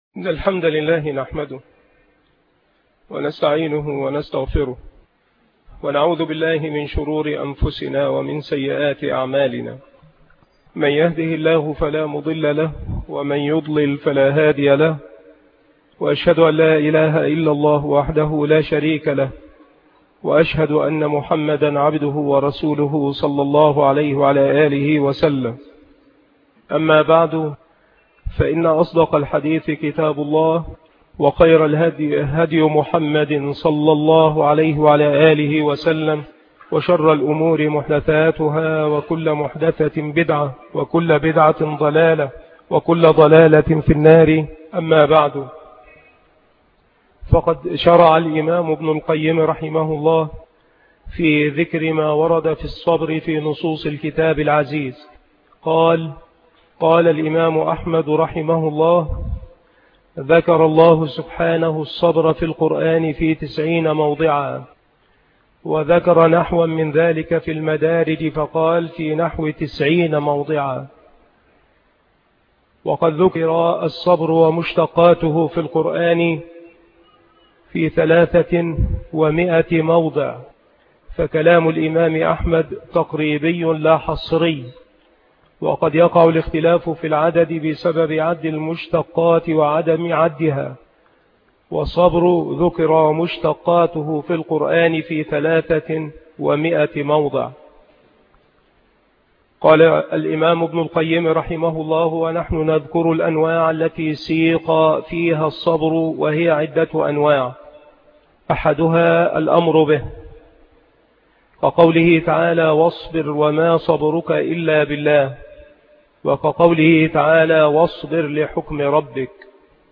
دروس